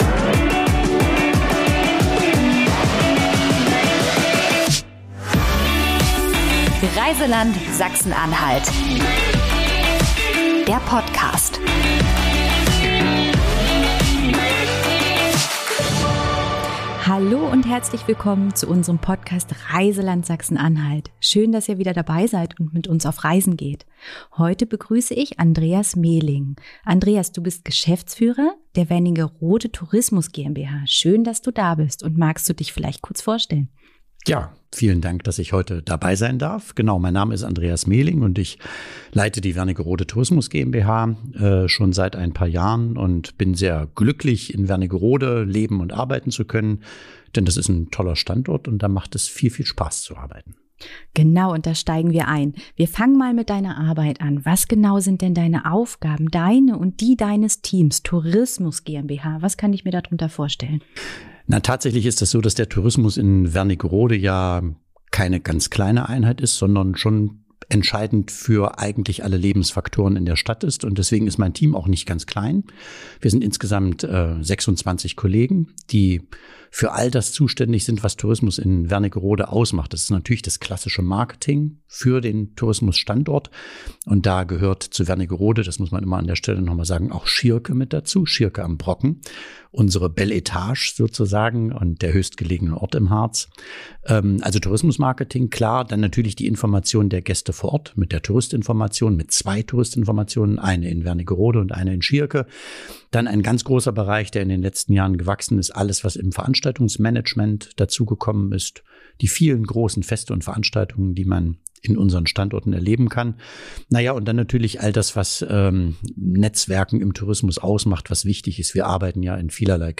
Wir plaudern mit interessanten Menschen, die uns erzählen, was man sich in ihrer Umgebung unbedingt anschauen sollte.